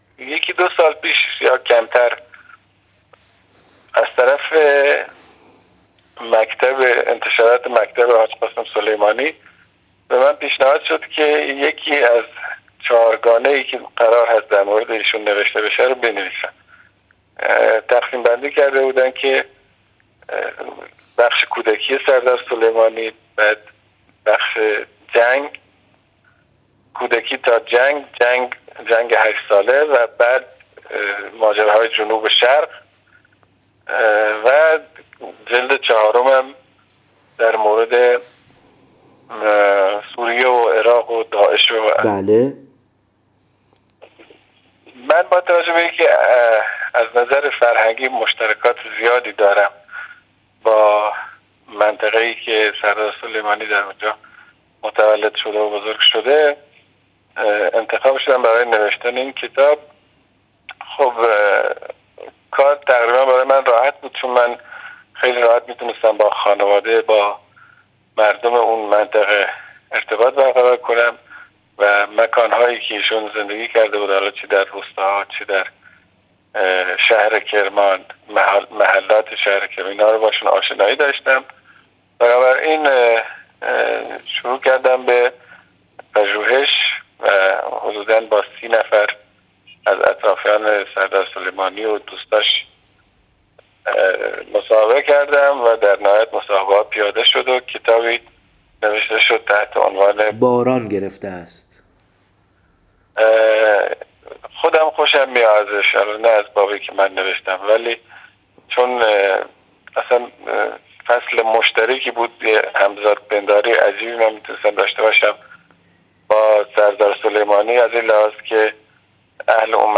مصاحبه مناسبت شهادت حاج قاسم//// باید به نوجوانان این زمان بگوییم حاج قاسم شبیه مسئولان امروز نبود + صوت